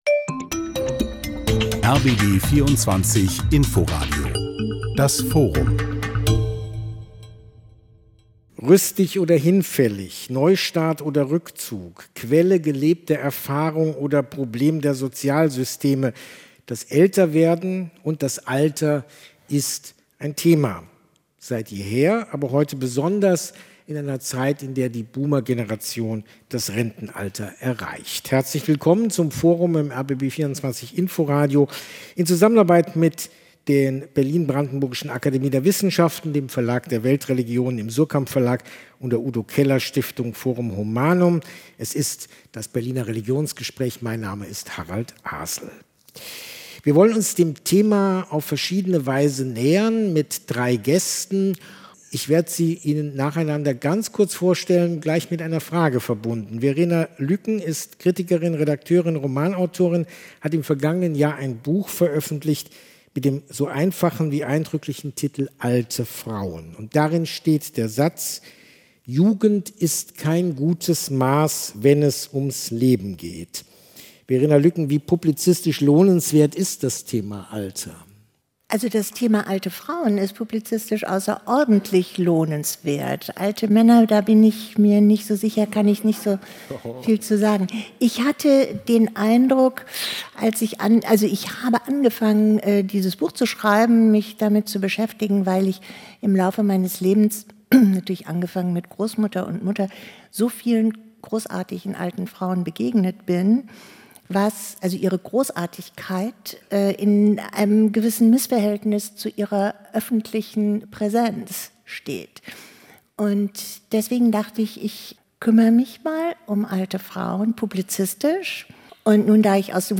Spannende Gespräche und Diskussionsrunden